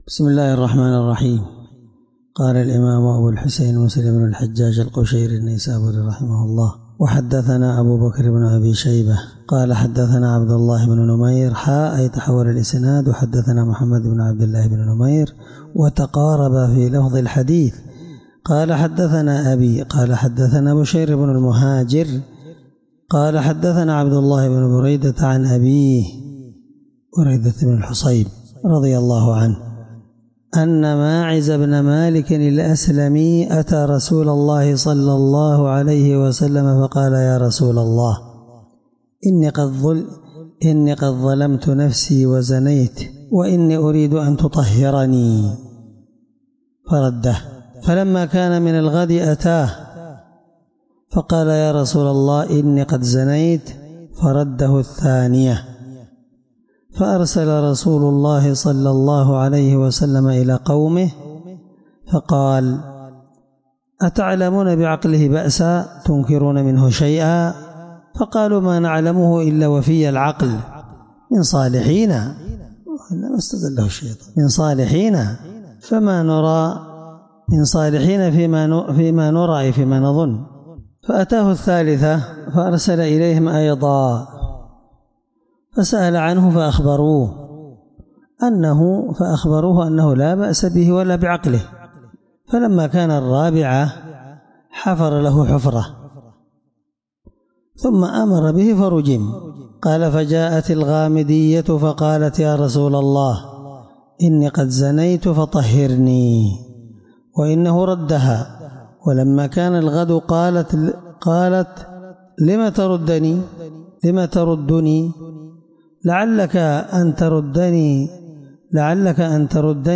الدرس8من شرح كتاب الحدود حديث رقم(000) من صحيح مسلم